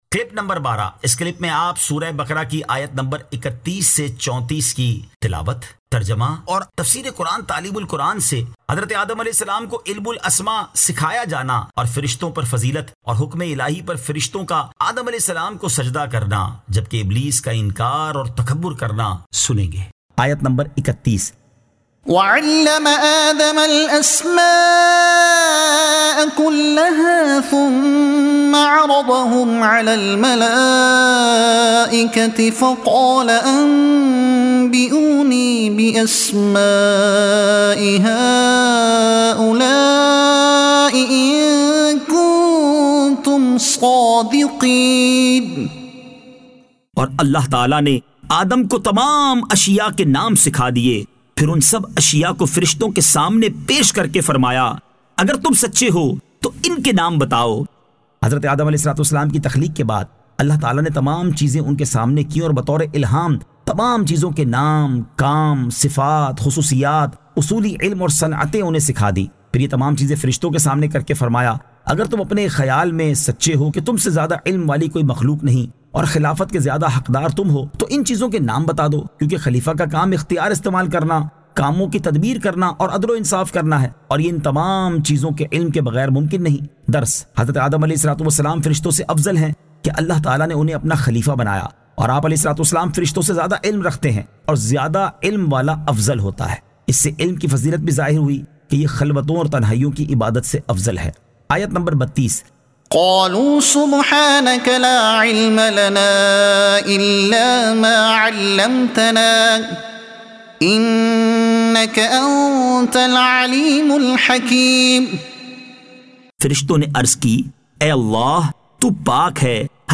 Surah Al-Baqara Ayat 31 To 34 Tilawat , Tarjuma , Tafseer e Taleem ul Quran